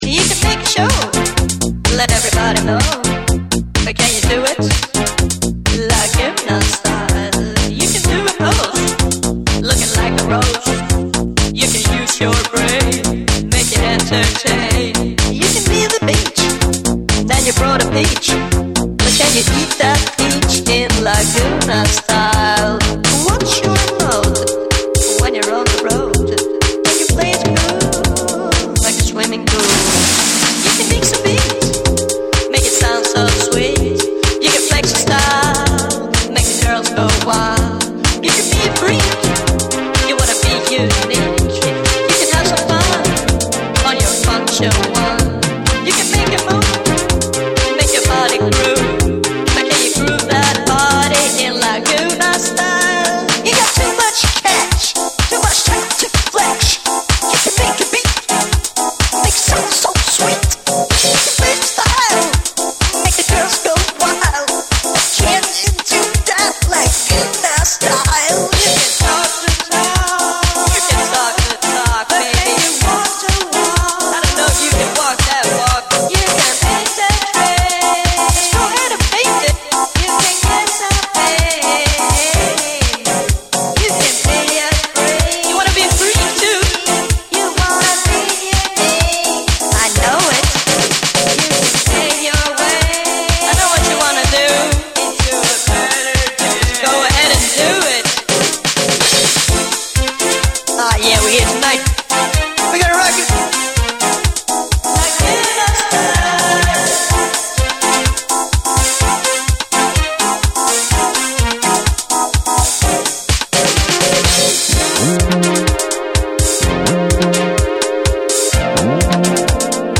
ソウルフルでエフェクティブな女性ヴォーカルをフィーチャーし、躍動感あふれるエレクトリックなディープハウスを展開！
TECHNO & HOUSE